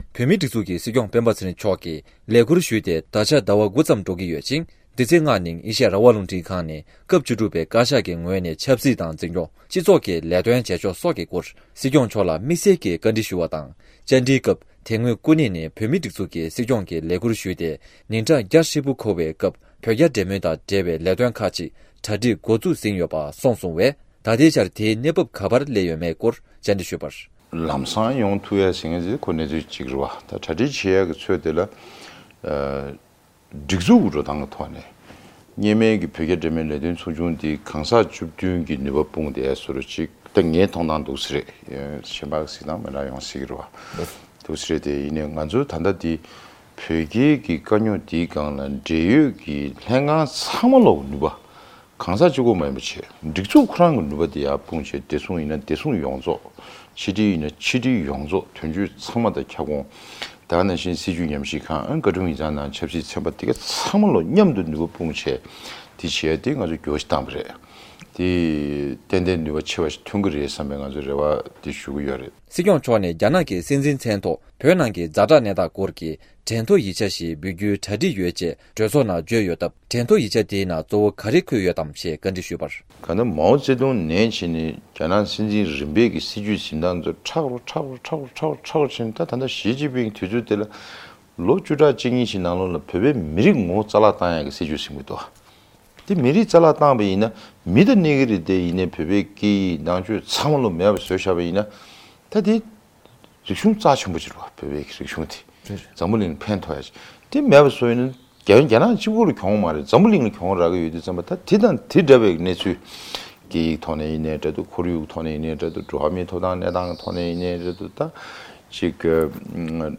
ཨ་རིའི་གྲོས་ཚོགས་འོག་མའི་ཚོགས་གཙོ་མཆོག་གིས་སྲིད་སྐྱོང་མཆོག་ཨ་རིར་གཞུང་འབྲེལ་མགྲོན་འབོད་གནང་ཡོད་འདུག སྲིད་སྐྱོང་མཆོག་ལ་དམིགས་བསལ་བཅར་འདྲིའི་སྐབས། ༠༤།༠༥།༢༠༢༢
སྒྲ་ལྡན་གསར་འགྱུར། སྒྲ་ཕབ་ལེན།